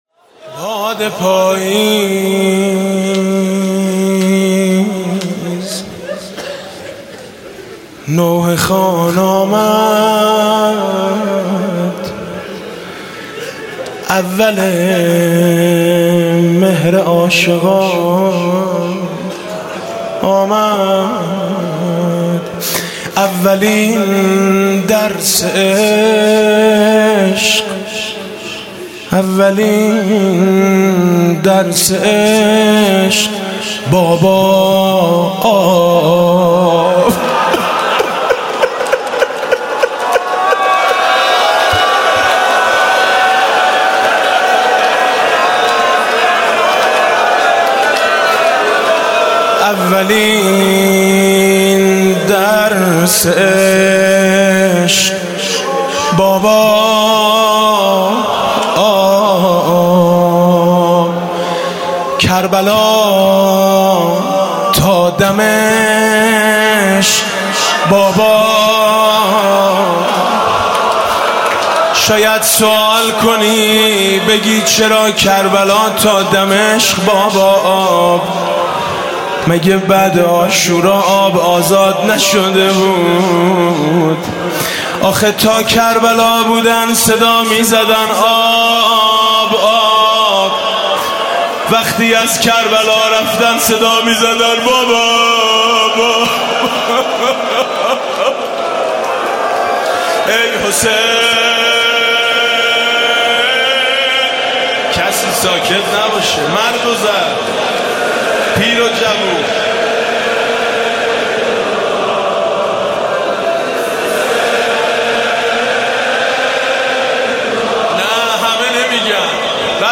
«محرم 1396» (شب اول) روضه: باد پاییز نوحه خوان آمد